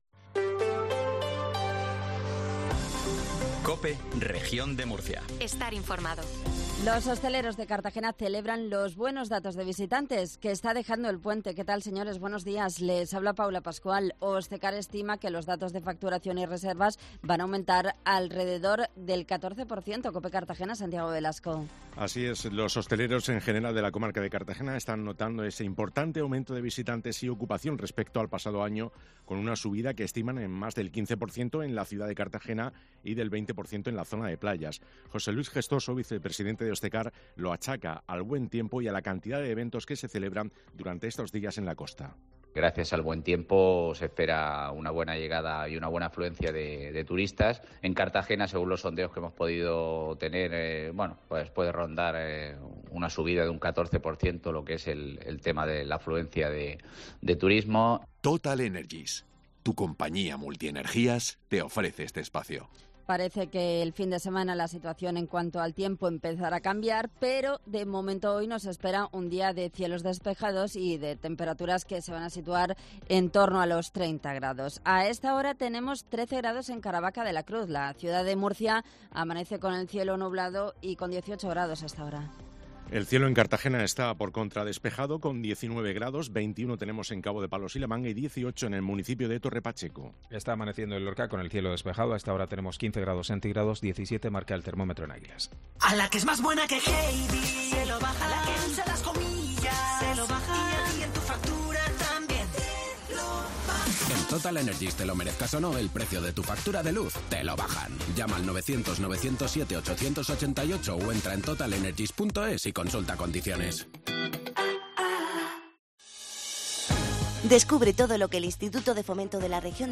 INFORMATIVO MATINAL REGION DE MURCIA 0750